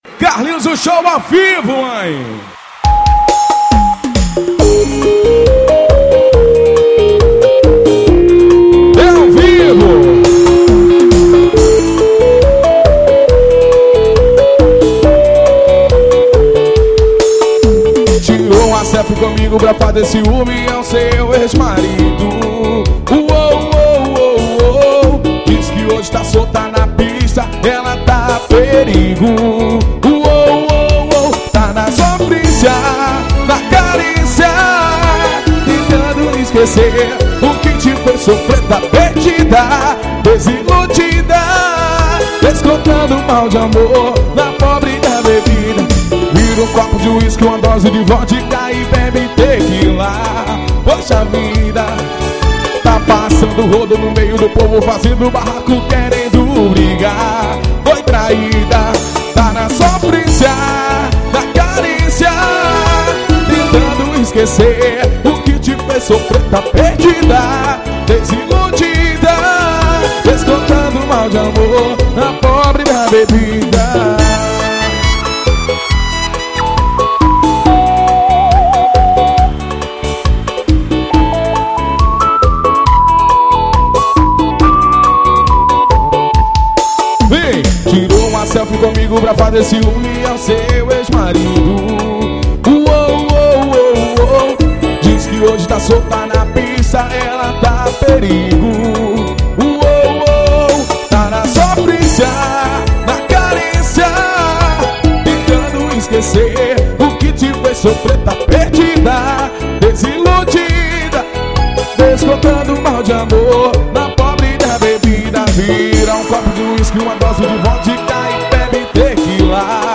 Show ao Vivo 2015.